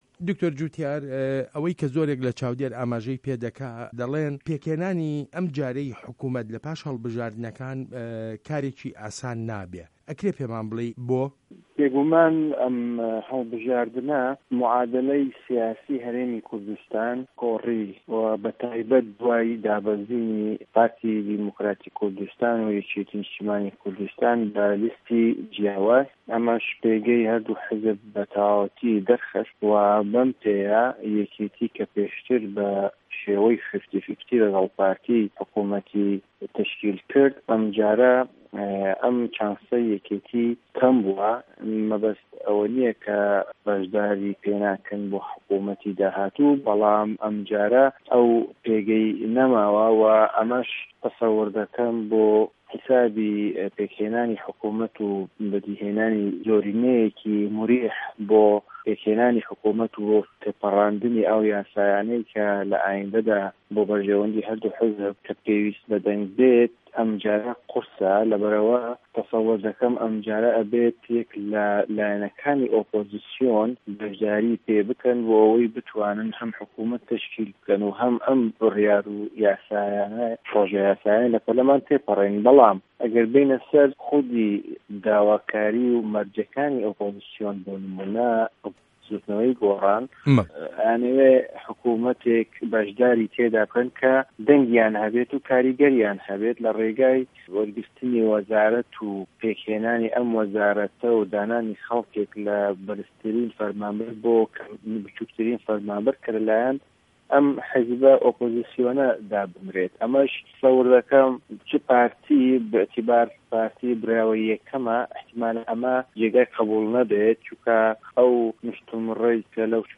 وتووێژ له‌گه‌ڵ دکتۆر جوتیار عادل